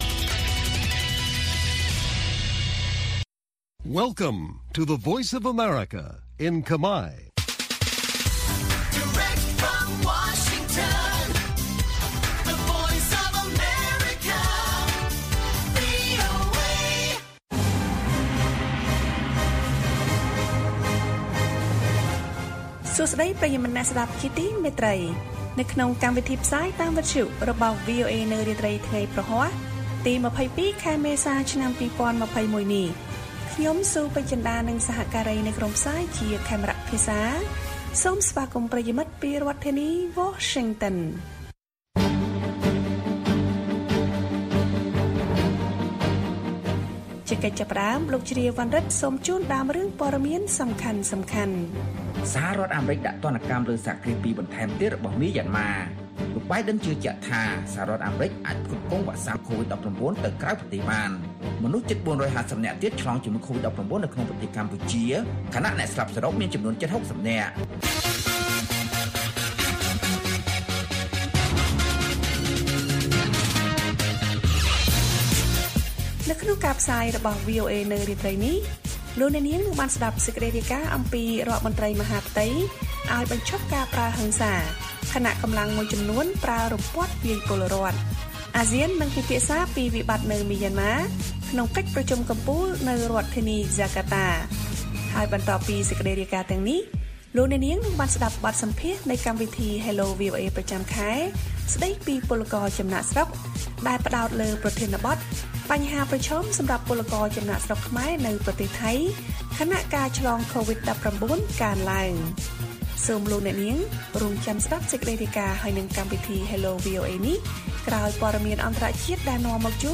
ព័ត៌មានពេលរាត្រី៖ ២២ មេសា ២០២១